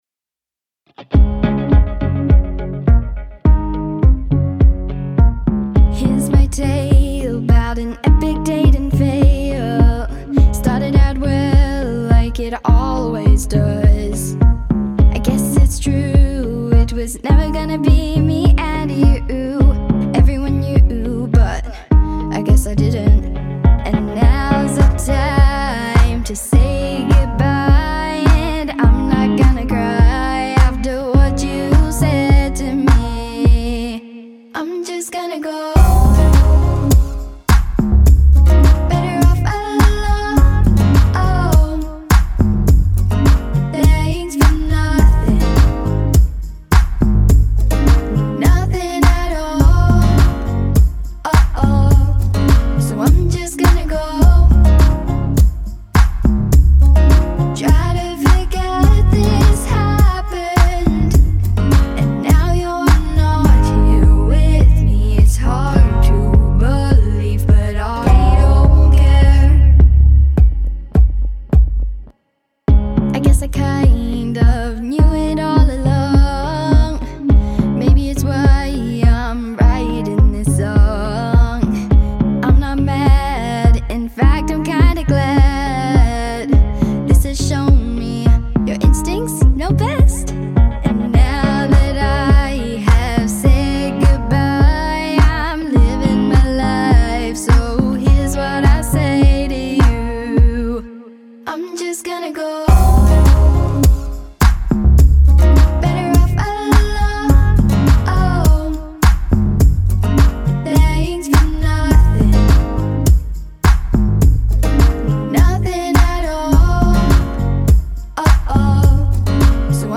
fun upbeat song